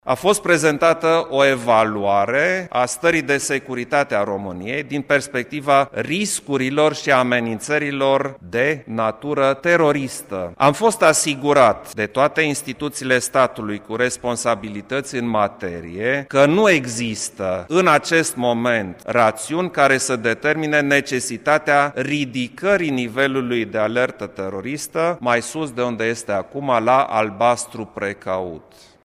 Așa a declarat președintele Klaus Iohannis la finalul ședinței CSAT.
Iohannis-CSAT.mp3